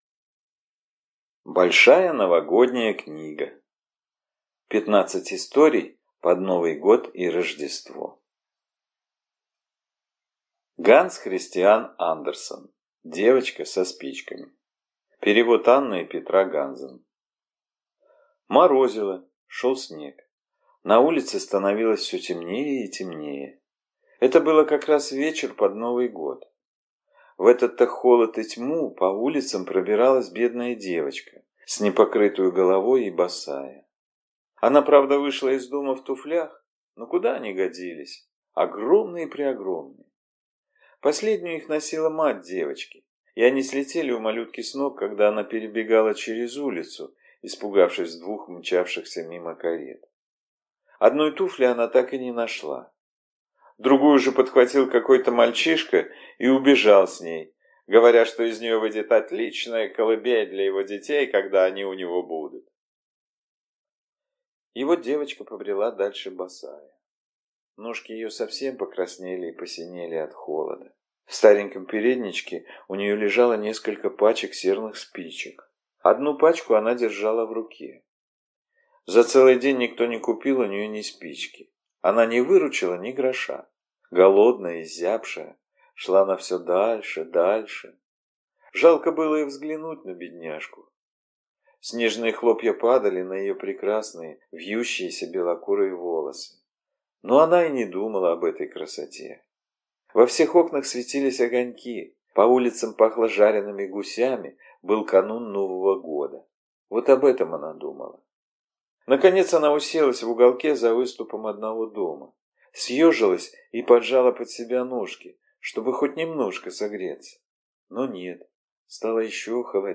Аудиокнига Большая Новогодняя книга. 15 историй под Новый год и Рождество | Библиотека аудиокниг